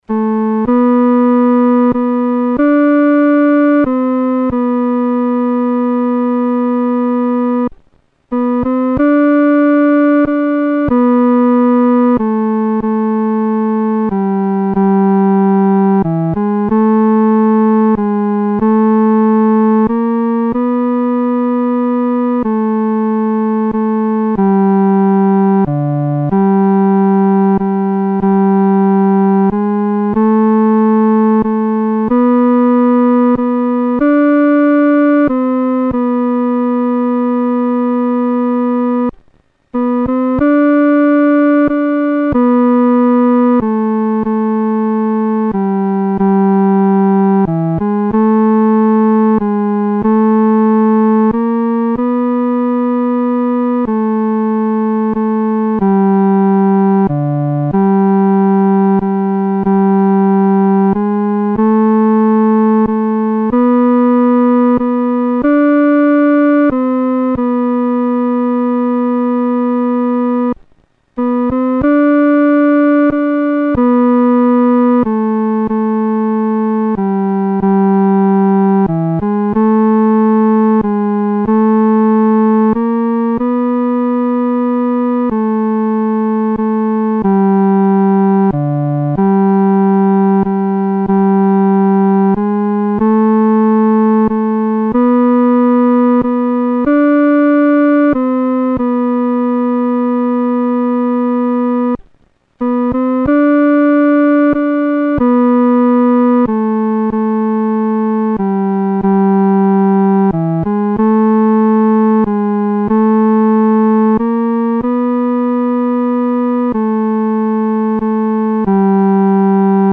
独奏（第三声）